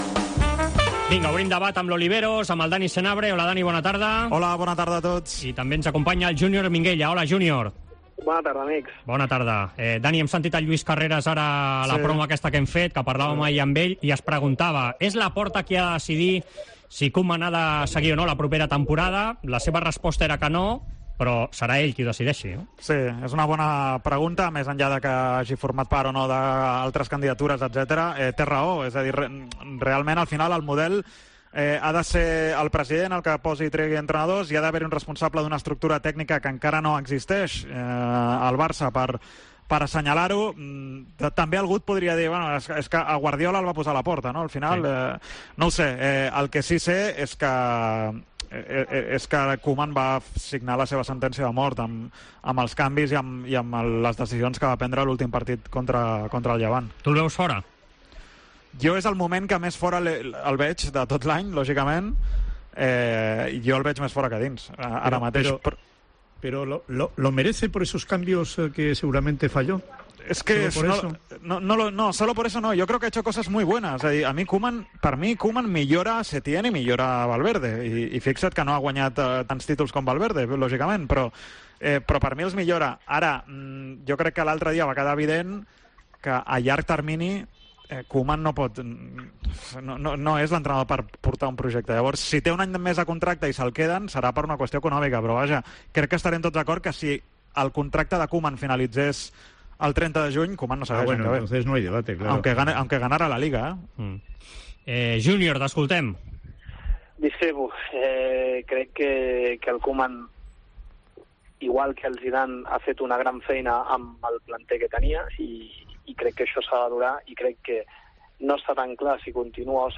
Debat